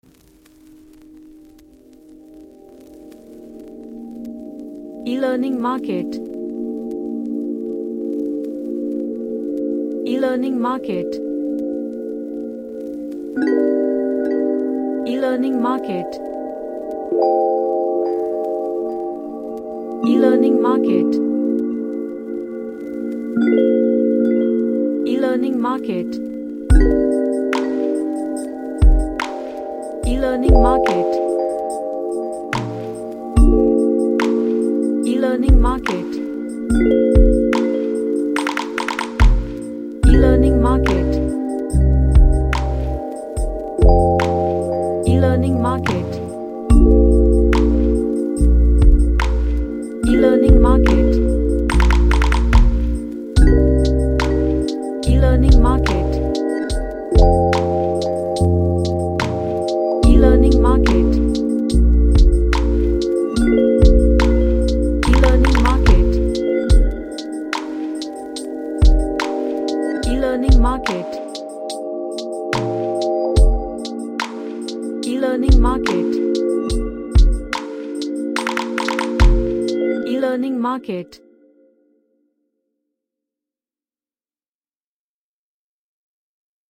A lofi track with gentle and chill feel
Chill OutMystery